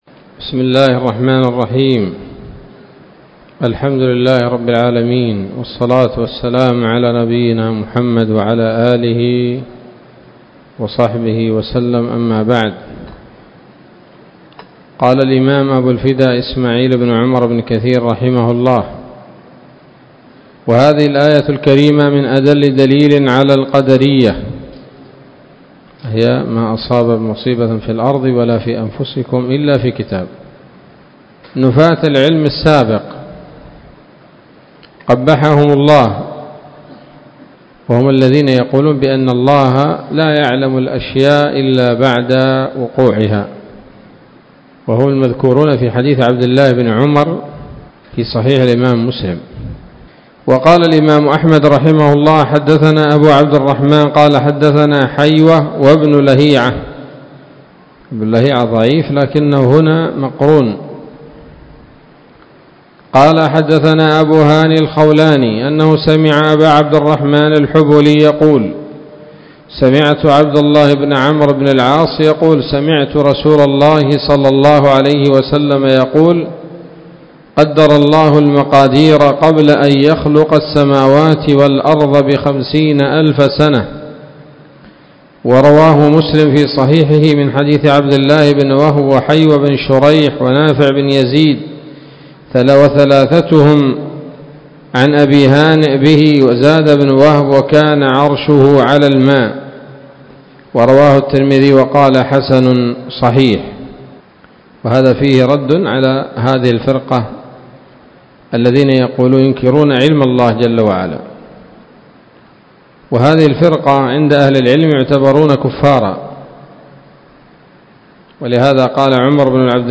الدرس العاشر من سورة الحديد من تفسير ابن كثير رحمه الله تعالى